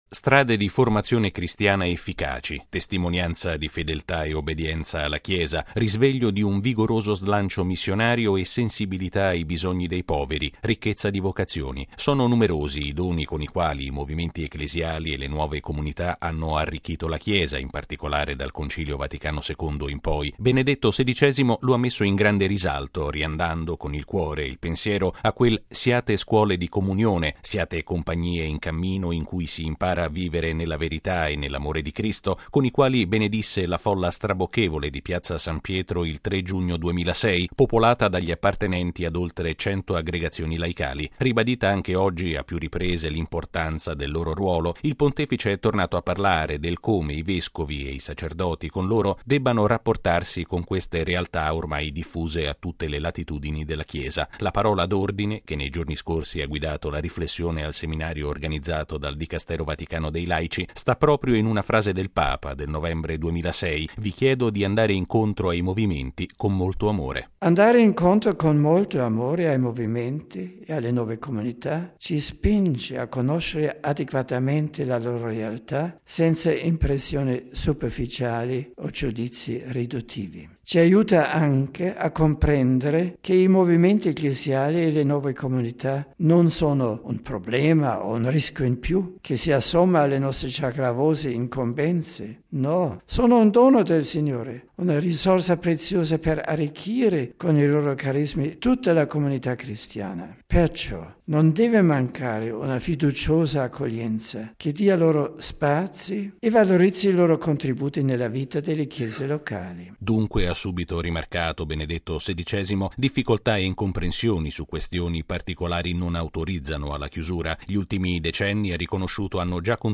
E’ il concetto di sintesi che Benedetto XVI ha espresso questa mattina parlando al gruppo di vescovi di tutti i continenti, che in questi giorni hanno partecipato a un Seminario di studio promosso dal Pontificio Consiglio per i Laici. Il servizio